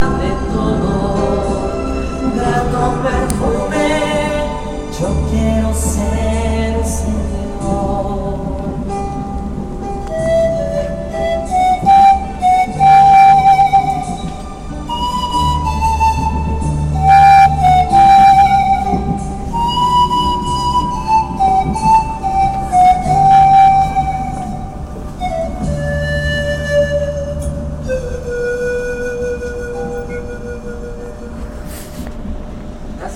Train Busker